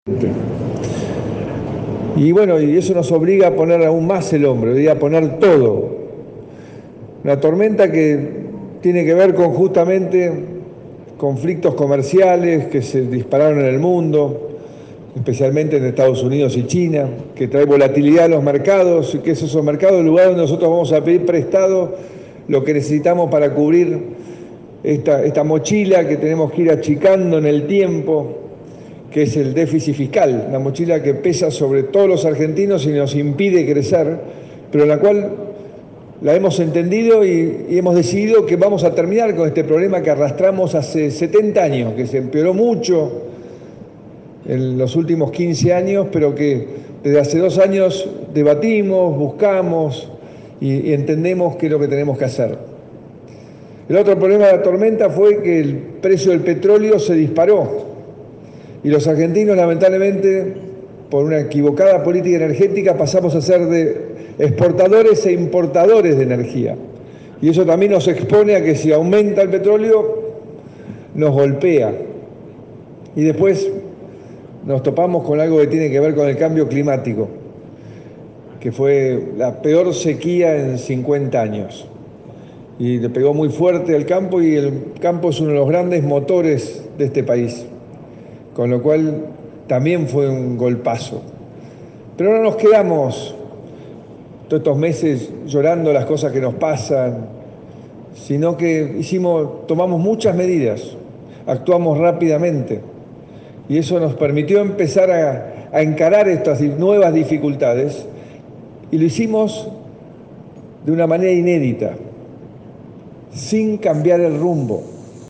EN VIVO | Mauricio Macri en Córdoba – Más Radio